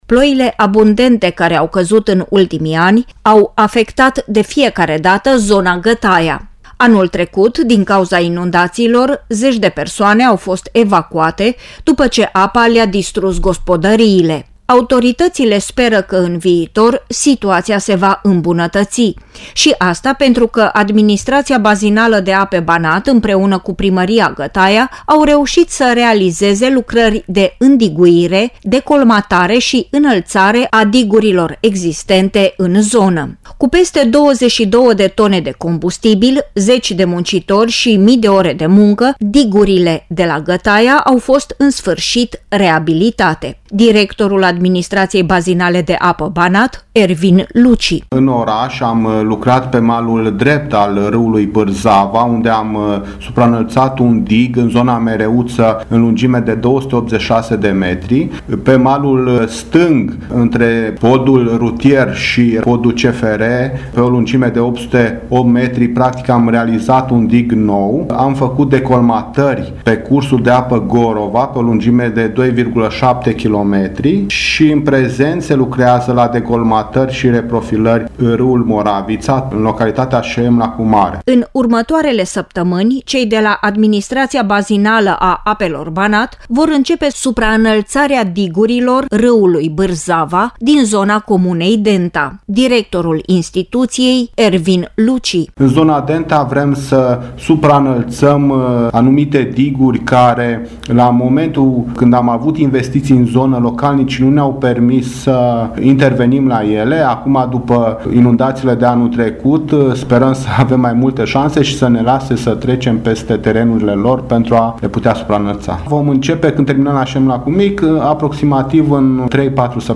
UPDATE sinteza emisiunii